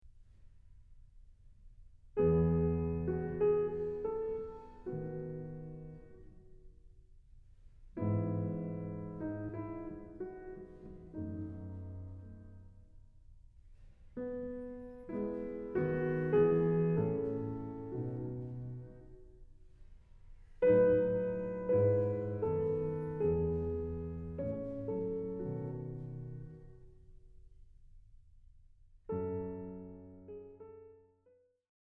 They are all in a Major key.